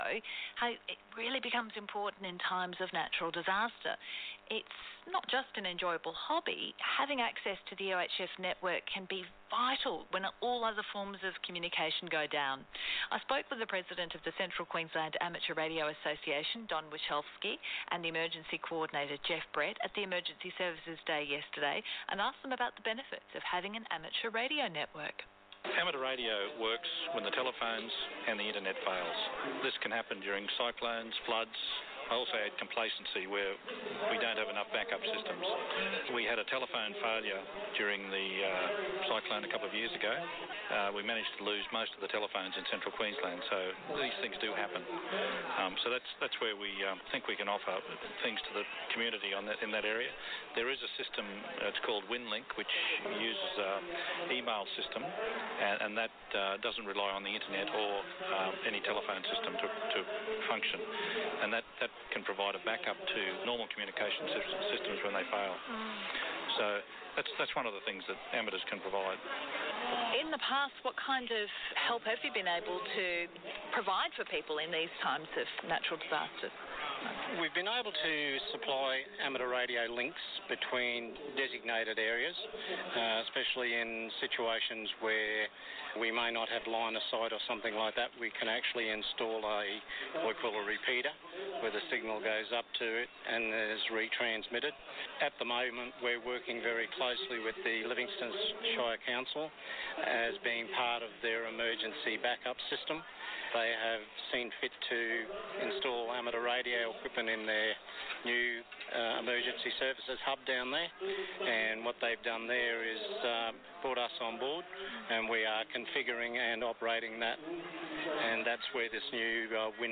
Emergency Services Day 2019
ABC Radio interview on the day.